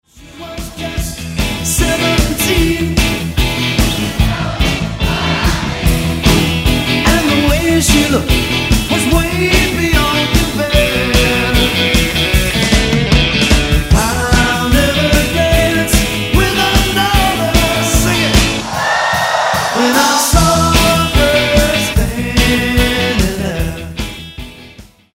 60's Music